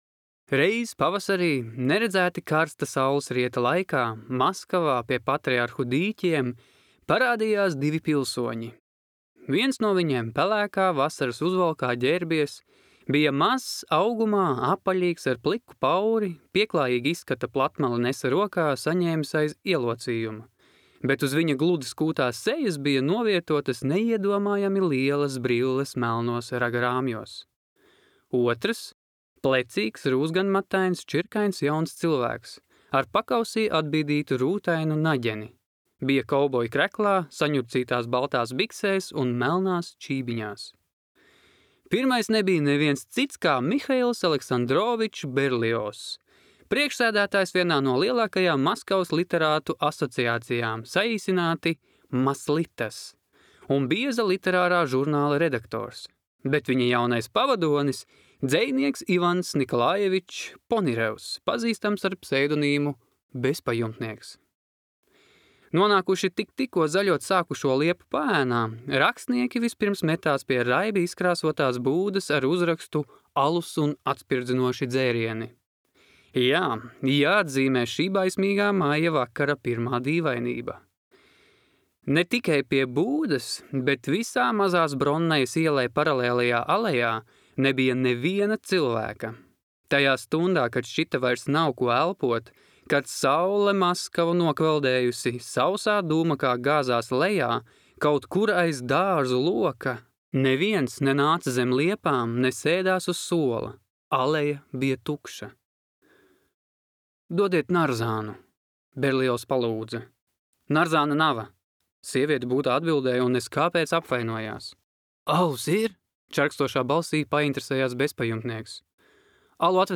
Un nu, pirmo reizi pilnā apjomā šis meistarīgais un bagātīgais laikmeta zīmogs ir ne tikai izlasāms, bet arī noklausāms latviešu valodā.